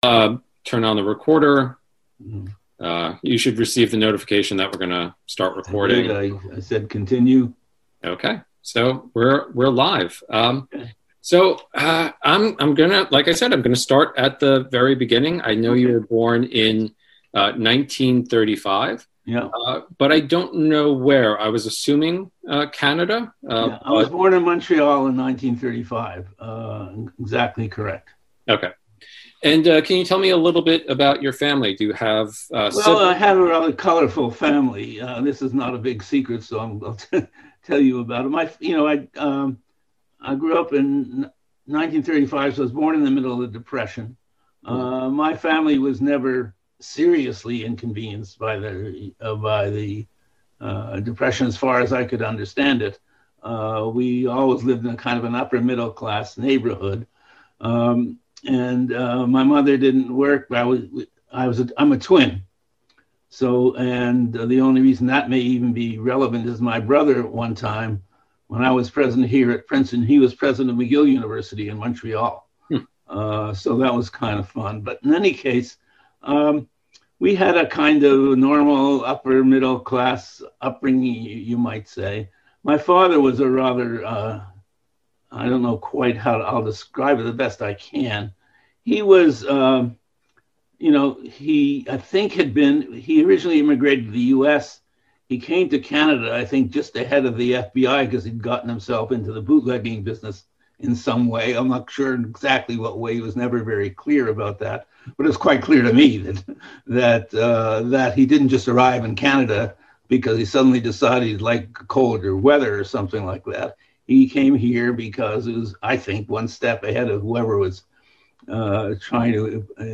Oral history interview with Harold T. Shapiro